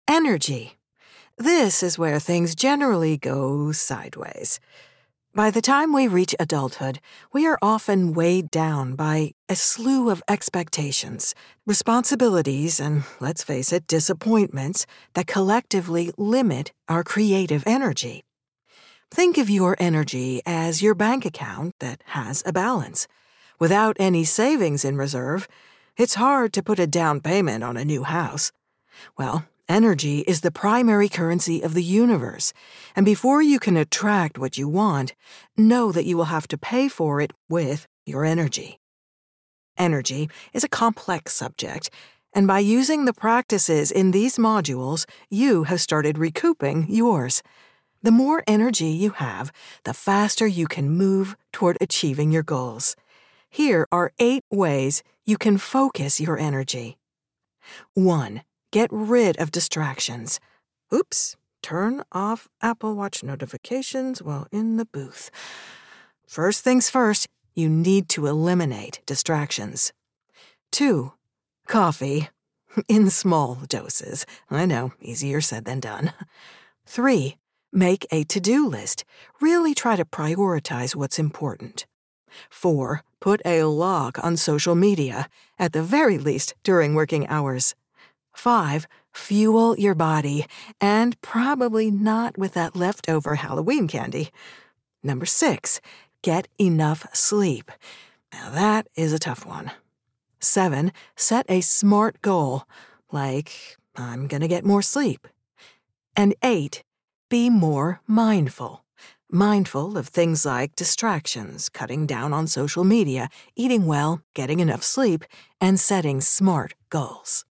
K-12 Commercial Narration Demo